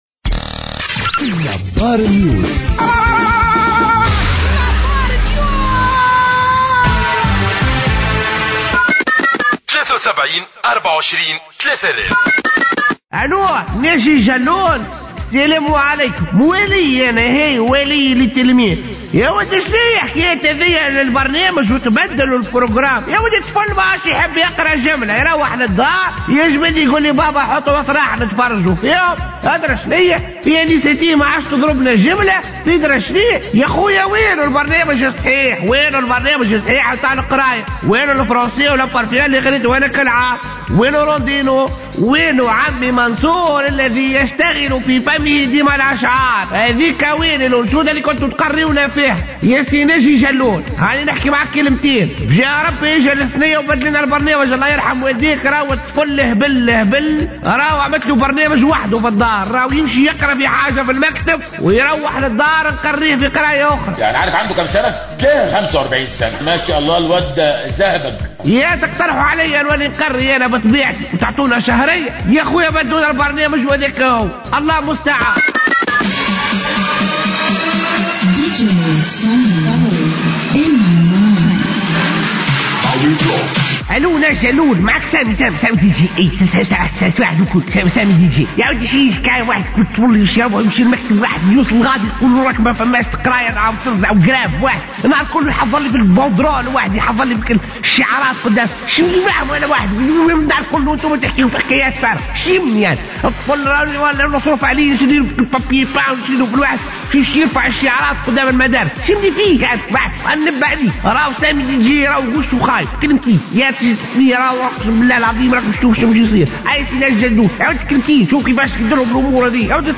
Revue de presse du lundi 5 Décembre 2016